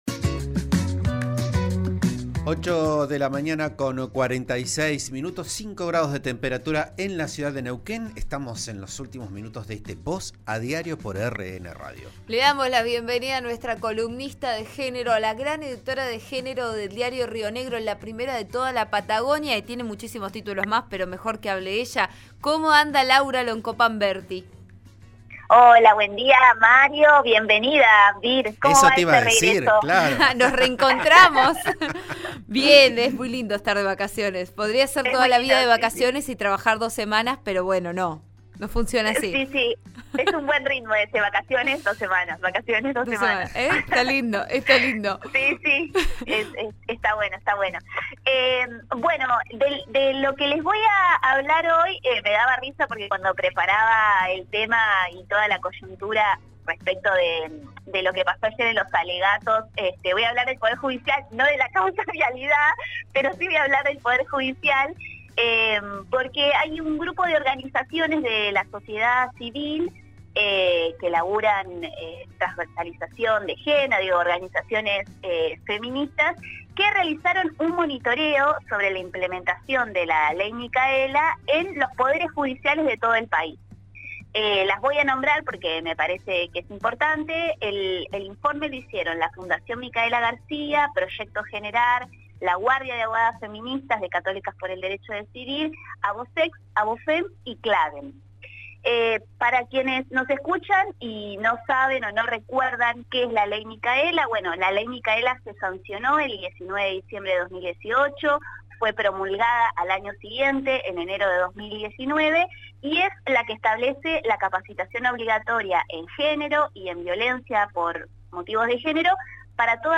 ¿Cuáles fueron las principales conclusiones? Lo podes escuchar en la columna de Género de hoy: